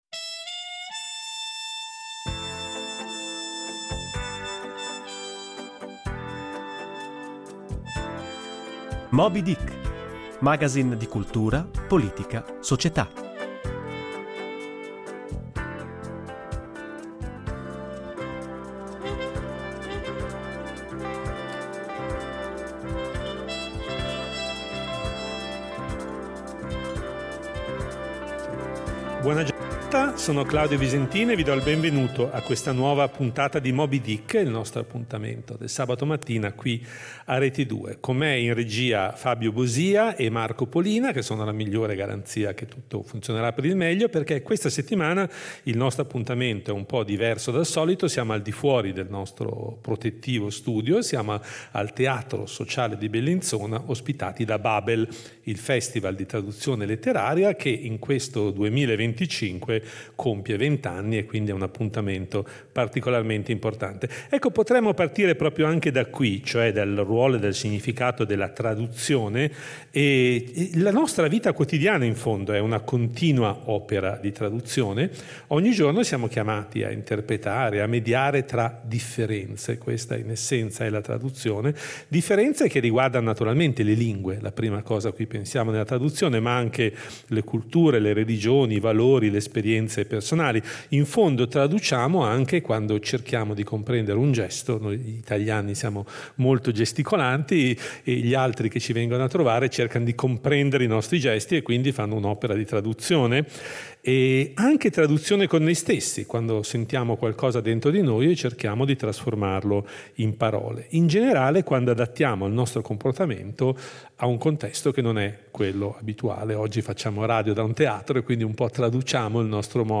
Per i vent’anni di Babel , il festival di traduzione letteraria, Moby Dick propone una puntata speciale in diretta dal Teatro sociale di Bellinzona.